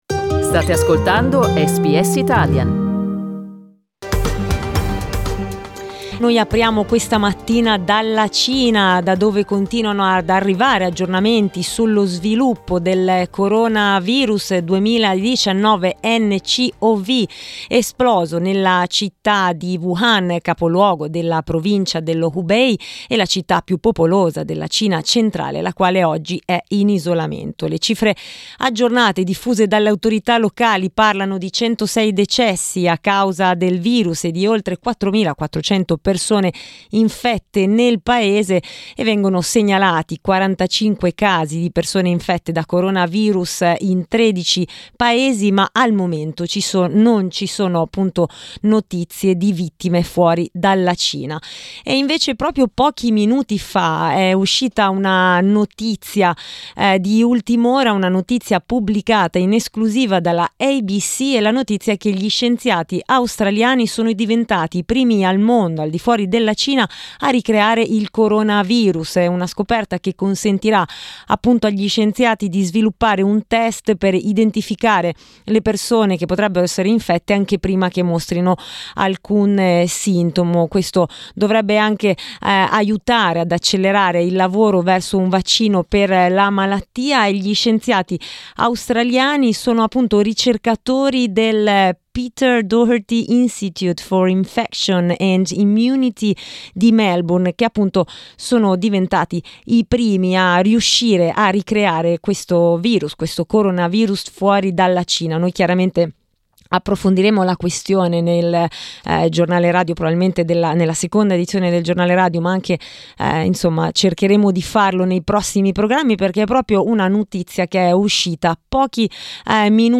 correspondent from Beijing